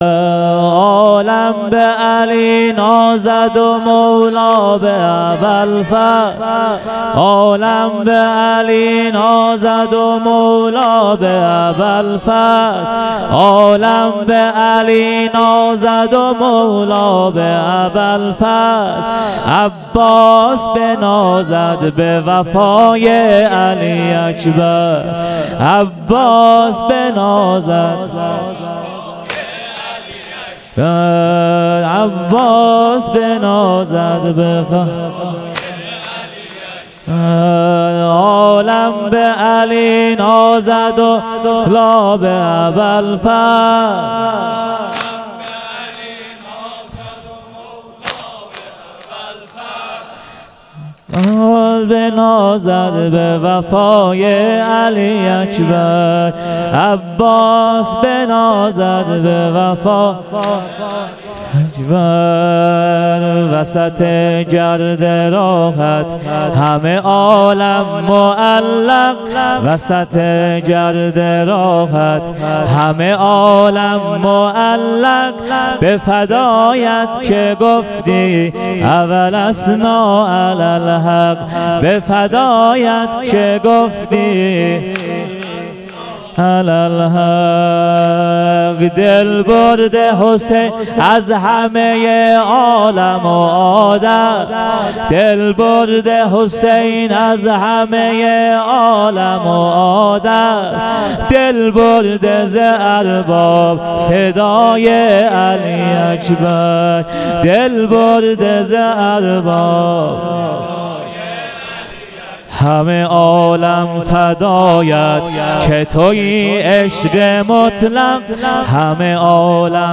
شب هشتم محرم 98 - بخش سوم سینه زنی (واحد)